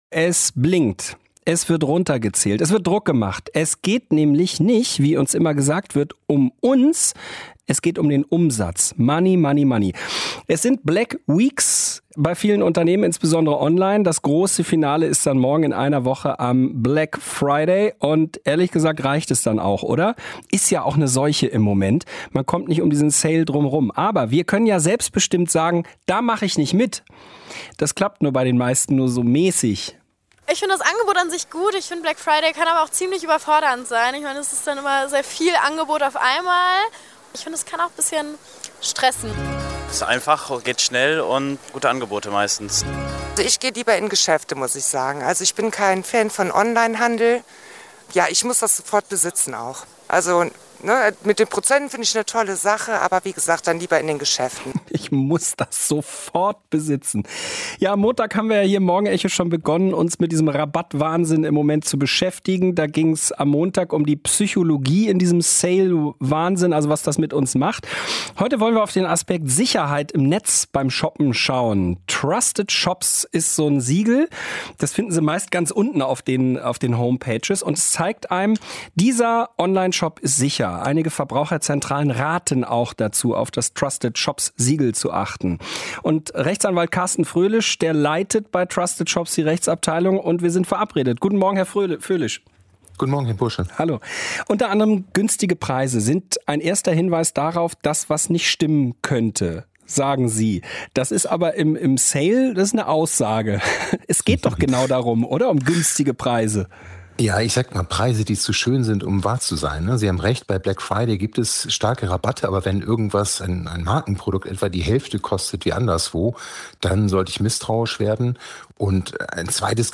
• Black Week: Sicheres Onlineshoppen, WDR 5 Morgenecho -Interview v. 21.11.2024